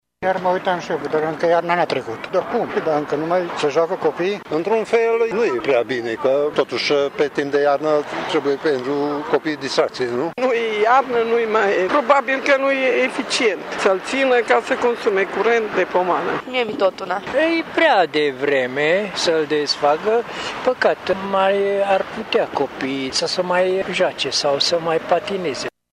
Unii regretă că patinoarul din centrul municipiului se desființează pentru că, deși temperaturile au mai crescut, e încă iarnă: